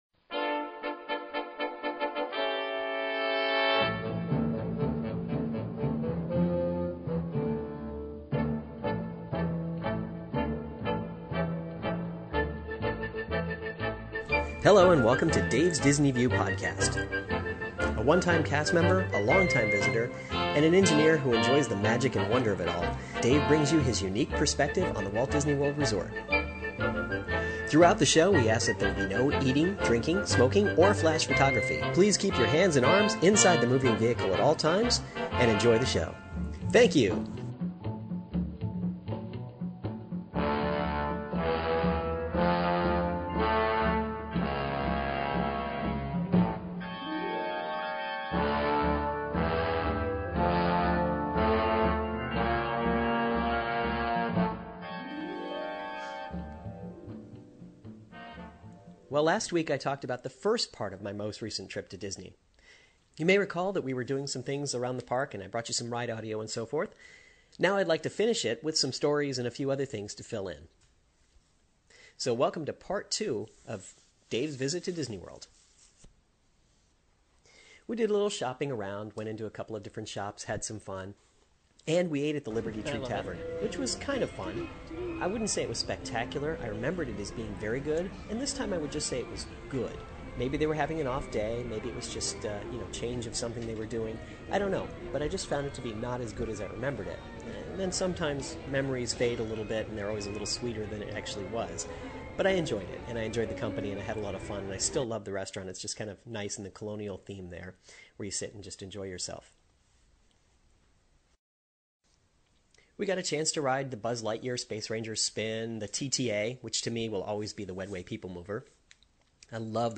In mid-February, I took a day trip to the Magic Kingdom. I brought my mp3 recorder, so that means you get to come along and spend the day with me as I make my way around the park! Listen in as I ride some attractions, and tell some stories.